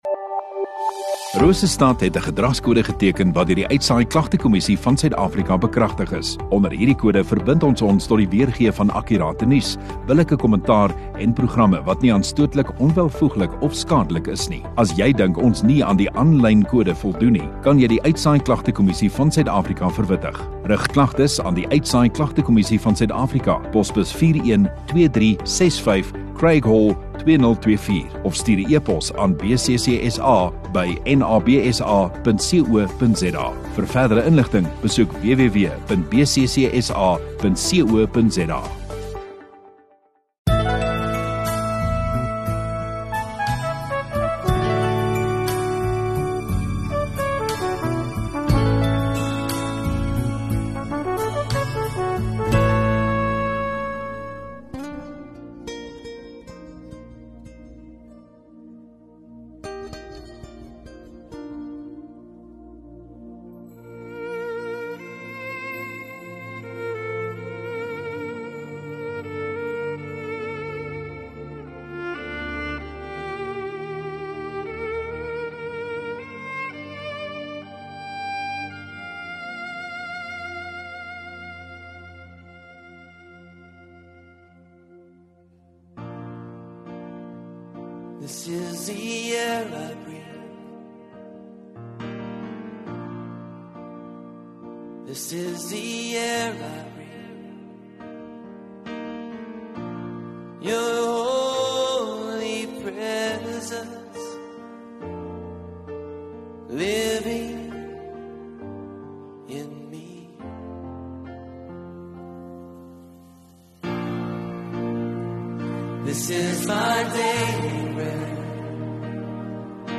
12 Jan Sondagoggend Erediens